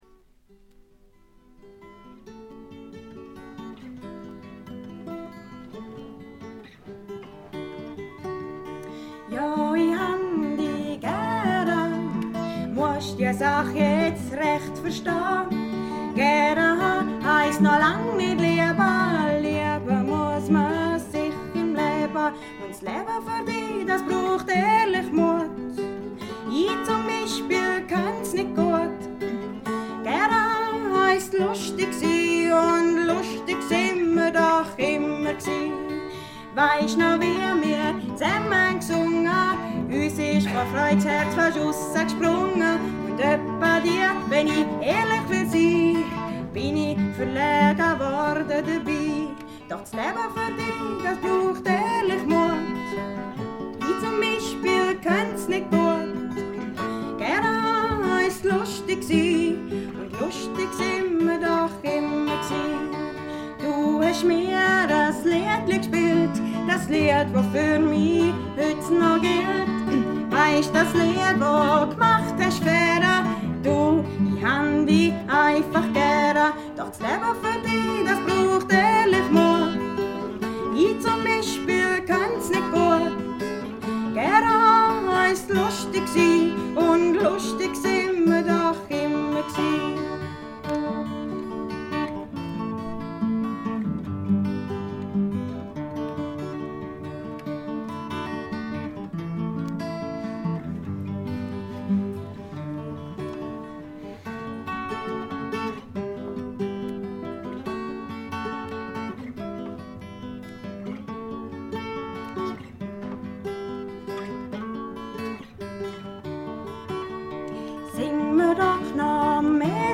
部分試聴ですが、軽微なチリプチが少し出る程度。
スイスのフォーク・フェスティヴァルの2枚組ライヴ盤。
試聴曲は現品からの取り込み音源です。